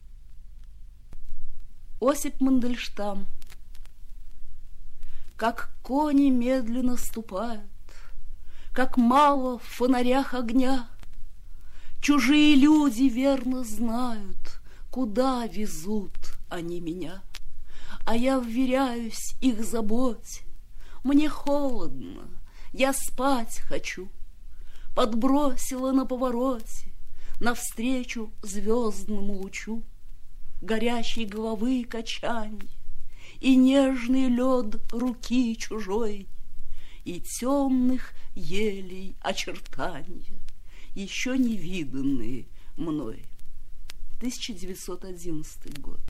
4. «Читает Б.Ахмадулина – Мандельштам. Как кони медленно ступают…» /